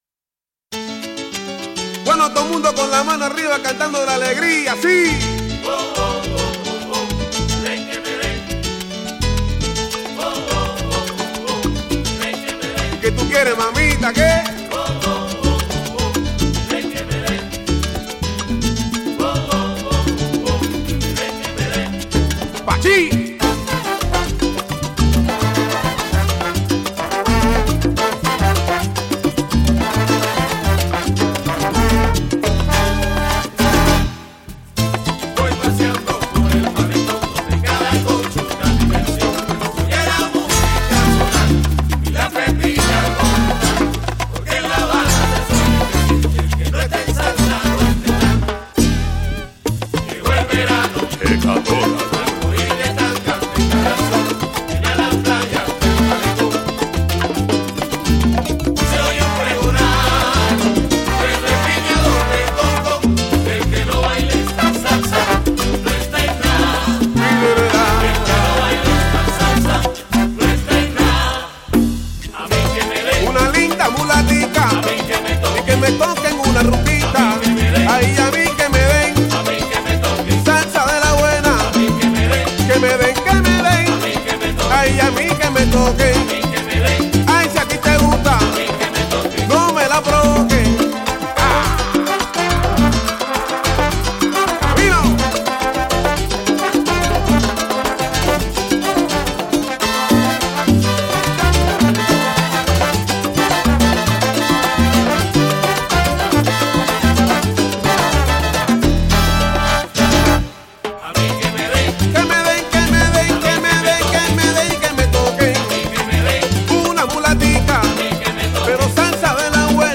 Žánr: World music/Ethno/Folk
Súčasná kubánska popová interpretácie salsy